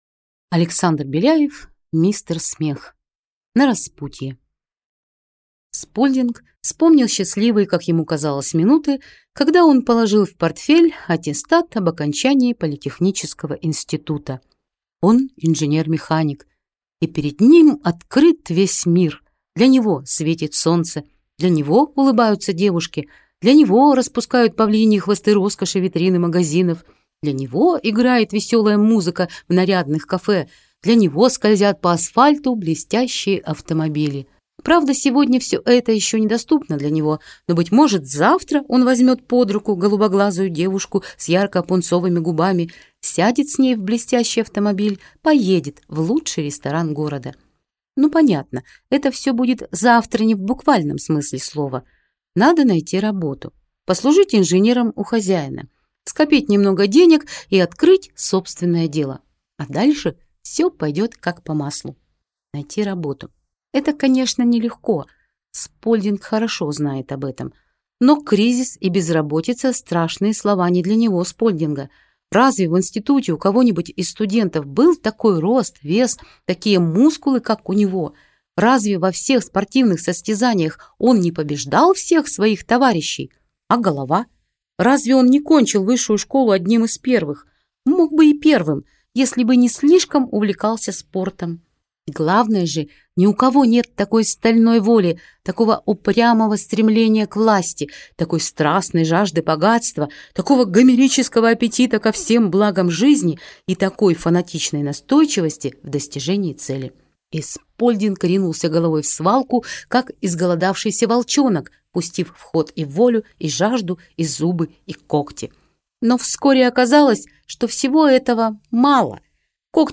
Аудиокнига Мистер Смех | Библиотека аудиокниг